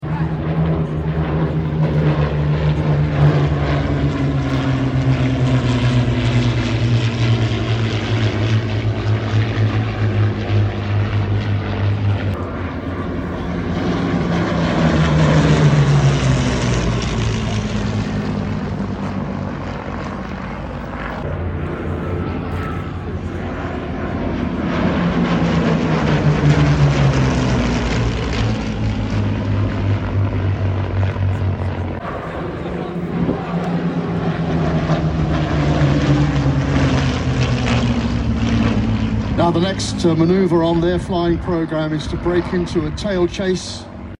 Spitfires and Hurricane formation at